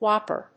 音節whóp・per 発音記号・読み方
/ˈwɑpɝ(米国英語), ˈwɑ:pɜ:(英国英語)/